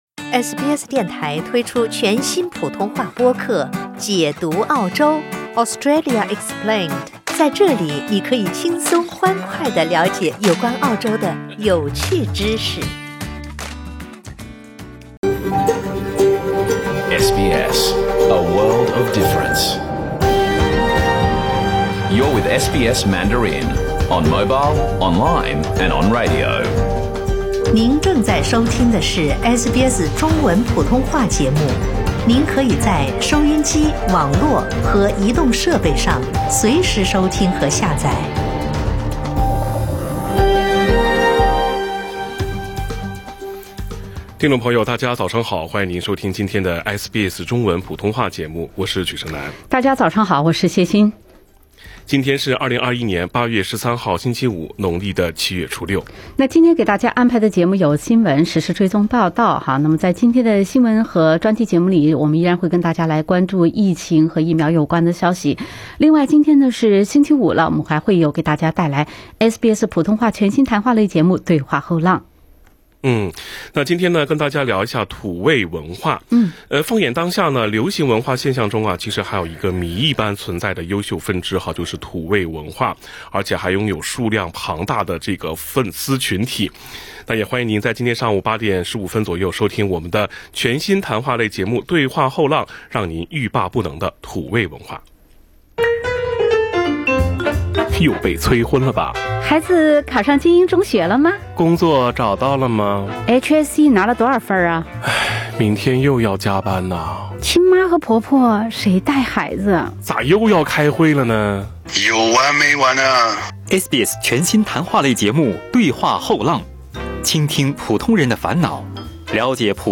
SBS Mandarin morning news Source: Getty Images